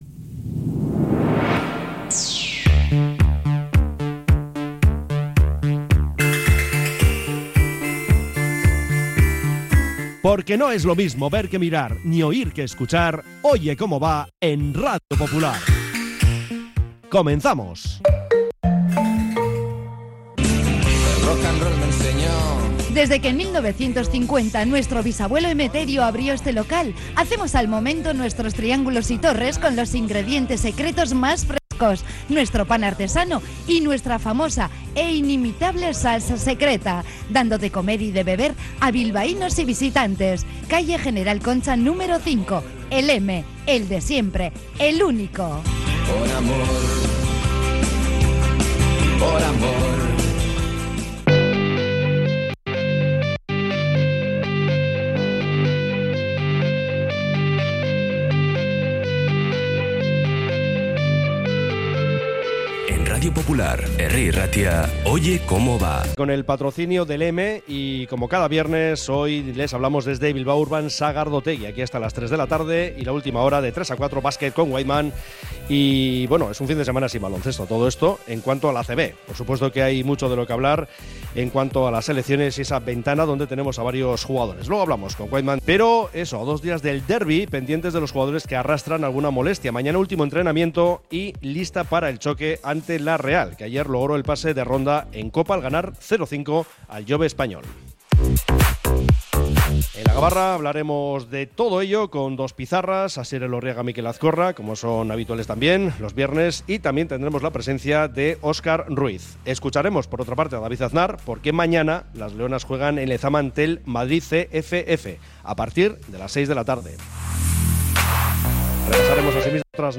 De 13:30 a 14:00 resumen informativo de la jornada con entrevistas y las voces de los protagonistas.